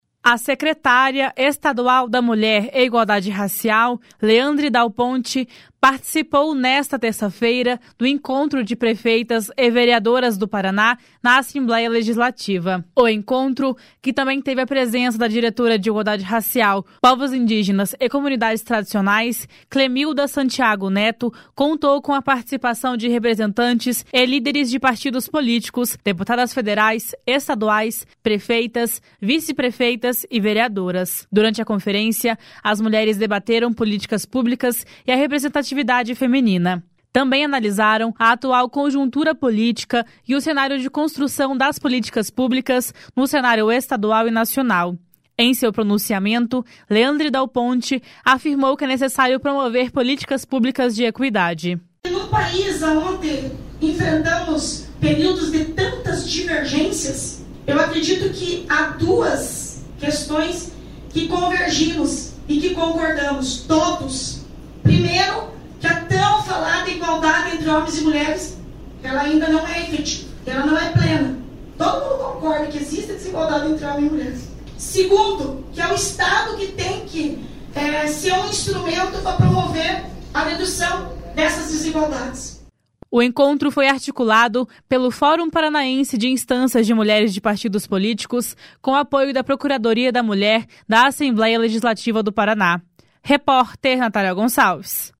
A secretária estadual da Mulher e Igualdade Racial, Leandre Dal Ponte, participou nesta terça-feira do Encontro de Prefeitas e Vereadoras do Paraná, na Assembleia Legislativa.
Em seu pronunciamento, Leandre Dal Ponte afirmou que é necessário promover políticas públicas de equidade. // SONORA LEANDRE DAL PONTE //